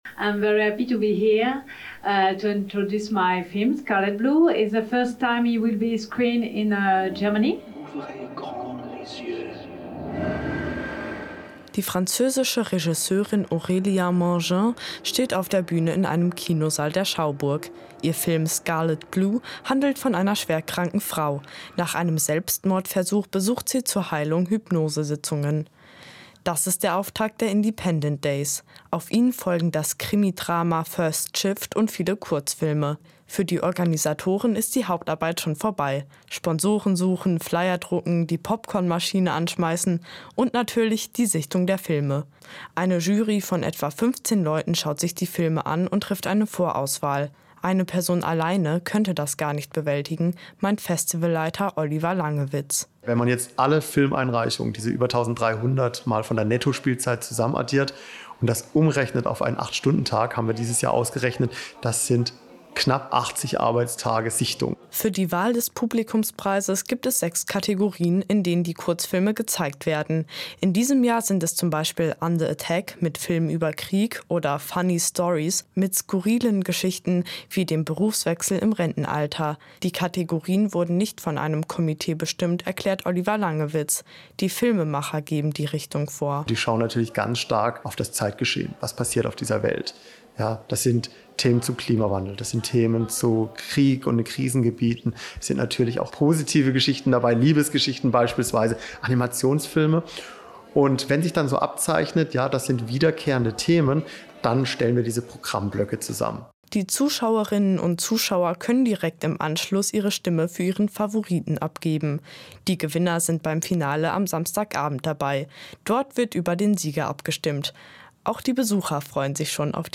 Der Beitrag lief am 10.04.2024 im Rahmen der "Aktuellen Kultursendung" auf dem Jungen Kulturkanal.